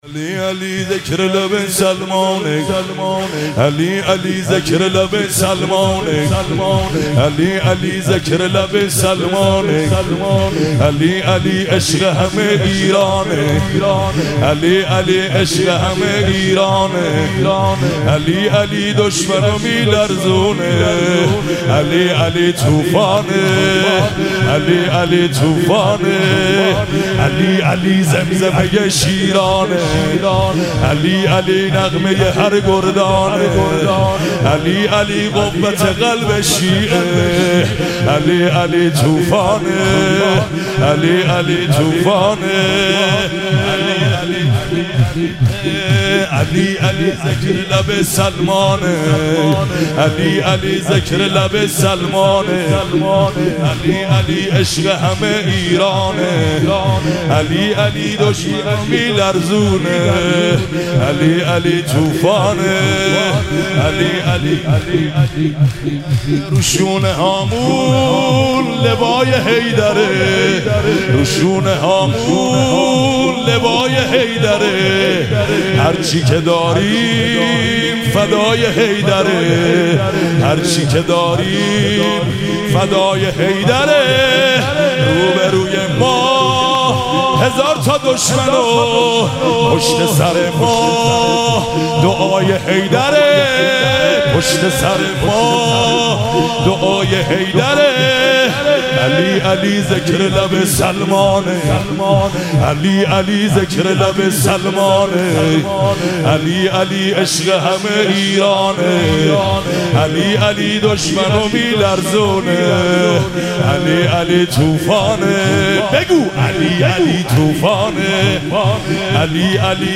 شور- علی علی ذکر لب سلمان
مراسم جشن شب اول ویژه برنامه عید سعید غدیر خم 1444